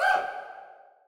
Samsung Ringtones